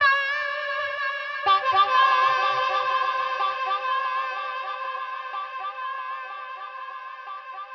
描述：迷幻主义
Tag: 124 bpm Ambient Loops Harmonica Loops 1.30 MB wav Key : Unknown